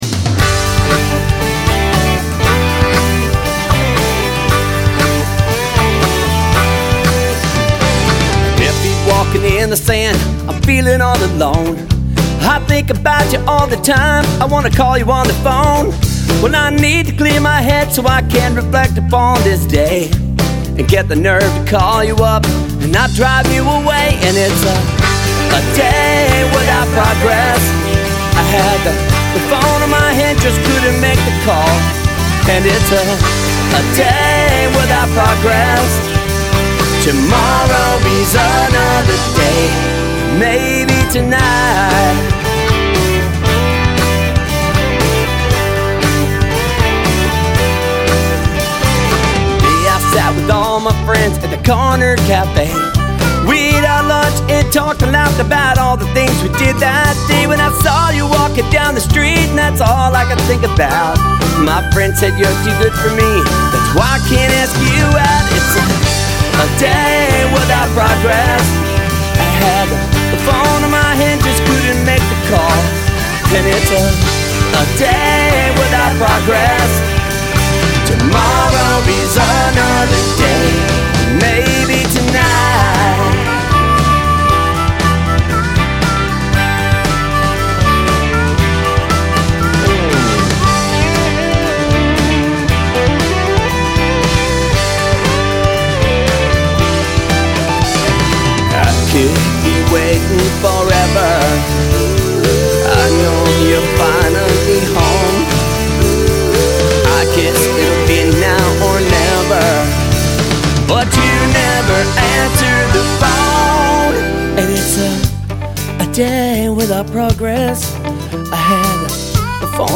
the harmonica sounds like a duck quacking in the intro
Lead Vocals
Drums
Bass Guitar
Acoustic and Electric Guitars:
B3 Organ
Harmonica
Background Vocals